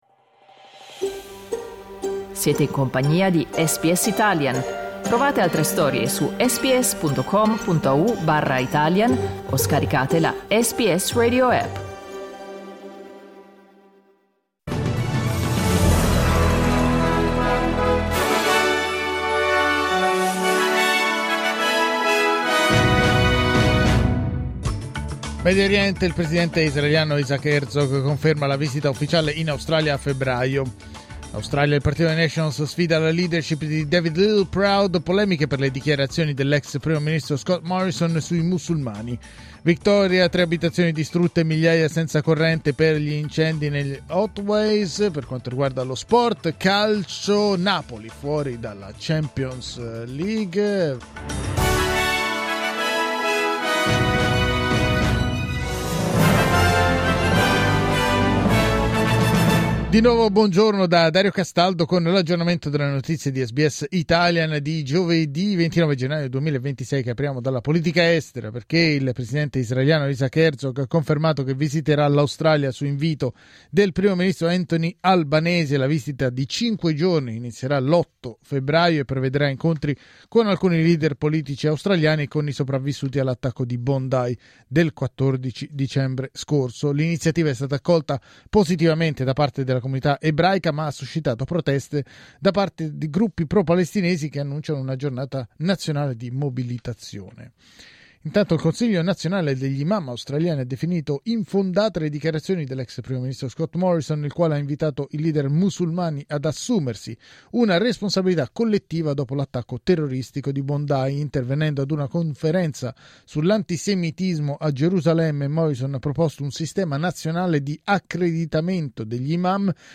News flash giovedì 29 gennaio 2026